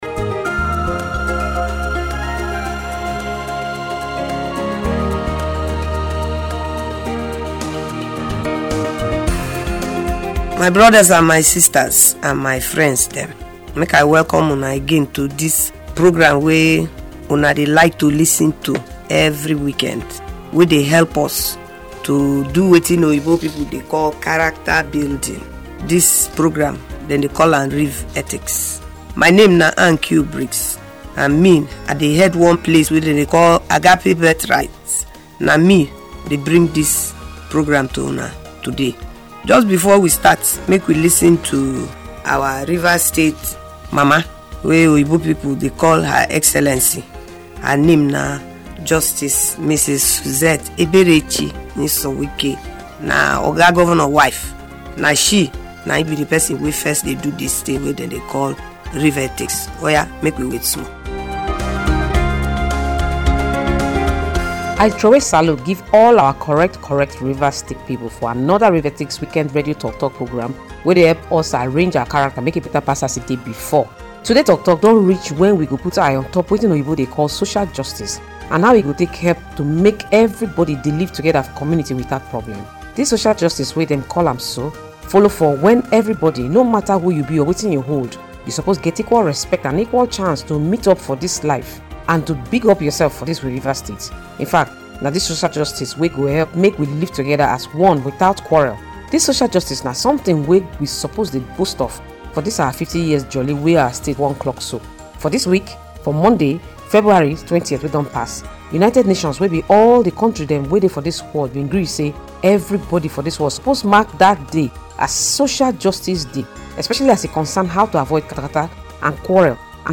In this episode of RivEthics, the weekend radio show, Annkio Briggs – President of Agape Birthrights, a non-governmental organization in the Niger Delta operating from Port Harcourt, and renowned advocate for social justice in Nigeria, particularly the Niger Delta , talks about Social Justice.